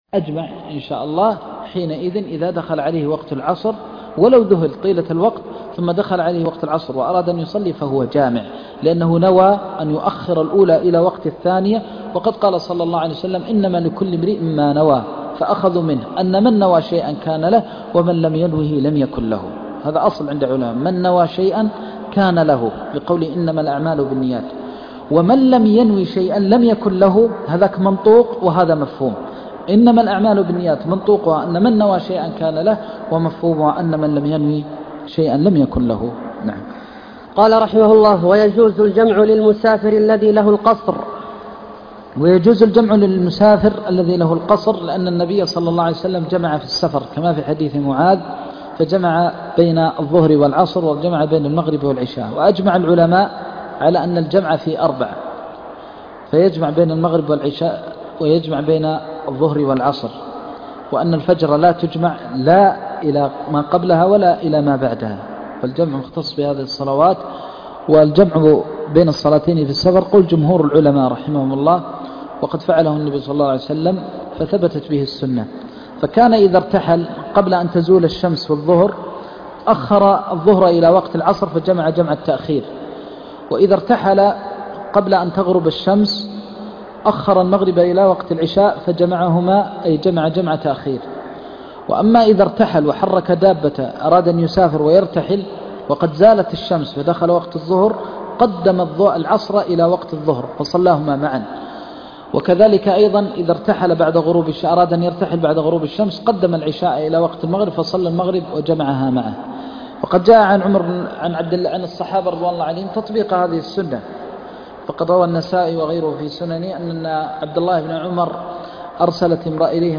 درس عمدة الفقه (15) - تابع كتاب الصلاة